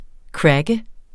Udtale [ ˈkɹagə ]